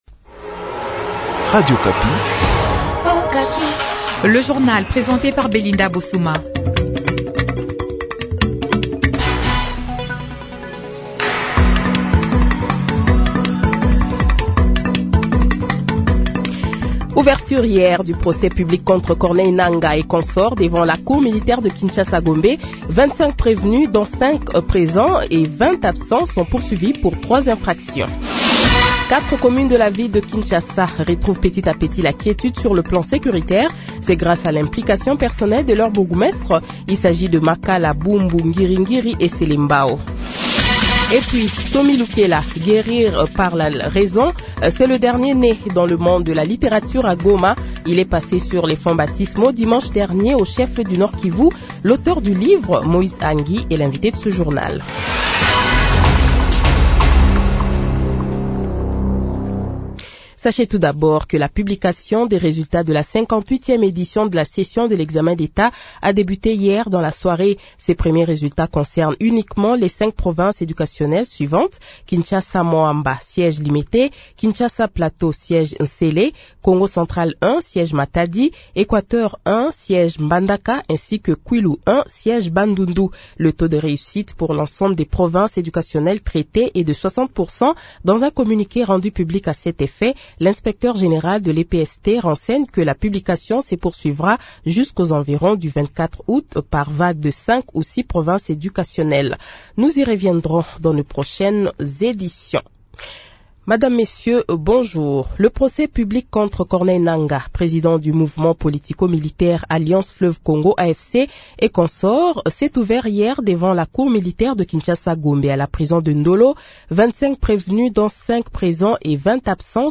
Journal Francais Matin
Le Journal de 7h, 25 Juillet 2024 :